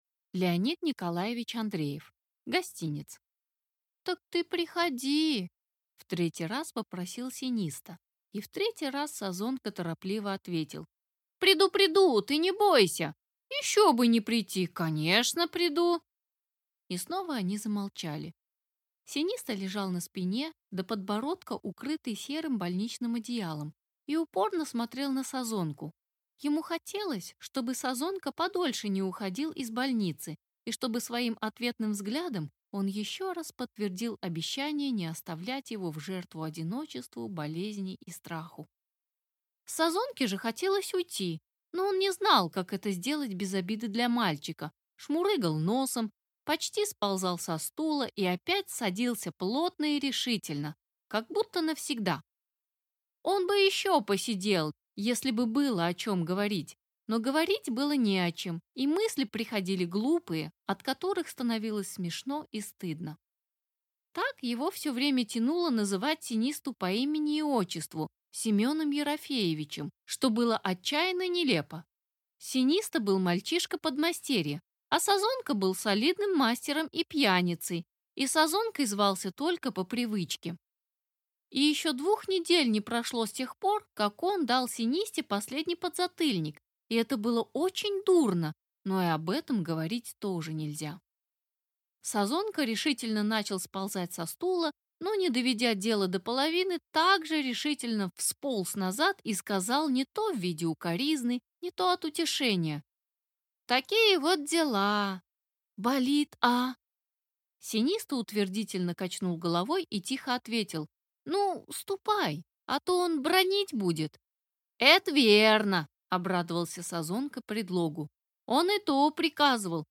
Аудиокнига Гостинец